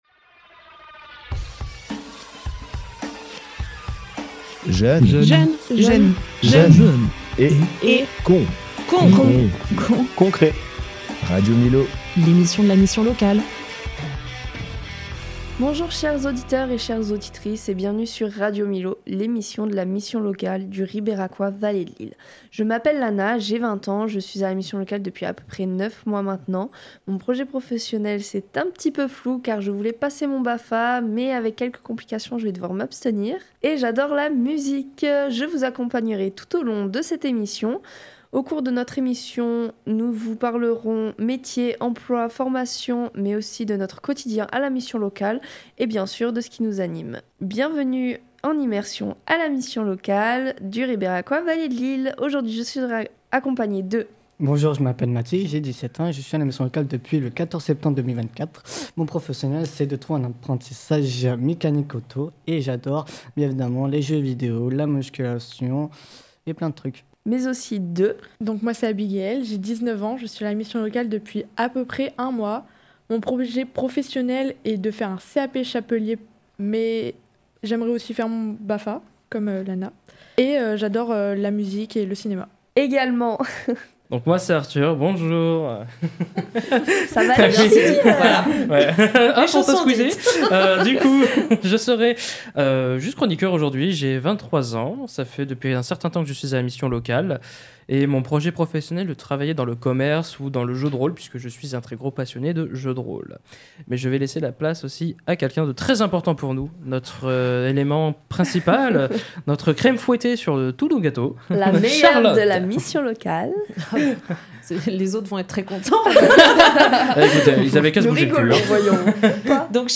La mission locale et des jeunes prennent le micros.